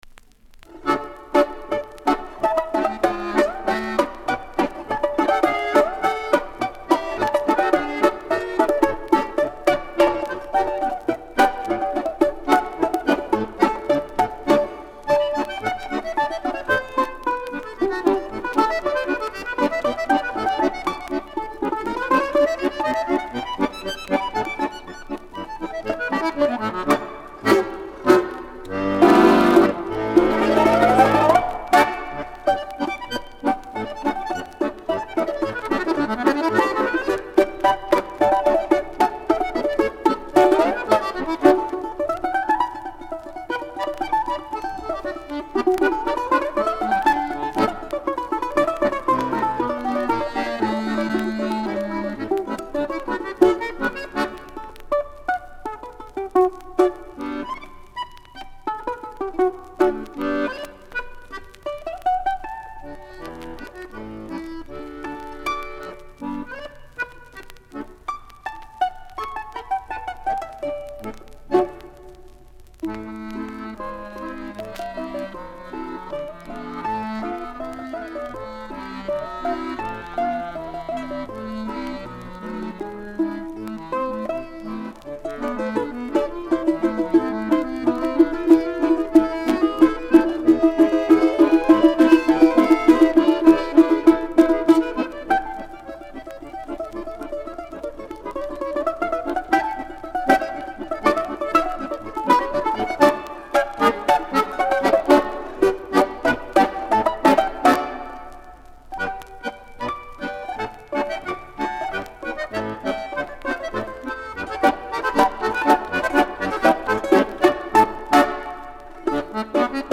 Kryizhachok_balalayka.mp3